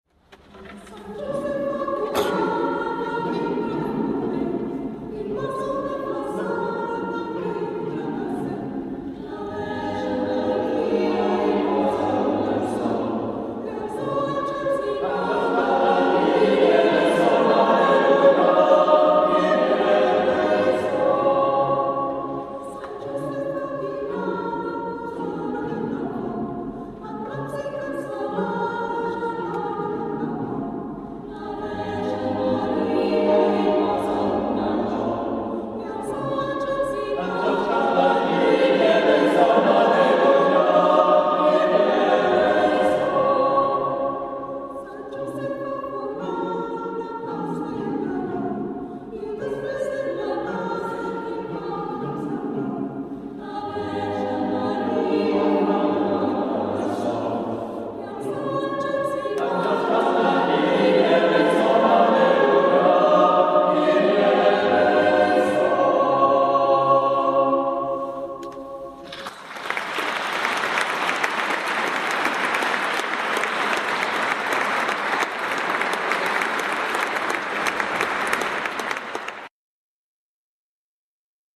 Formación:coro SATB
Género:Canción popular / Nadala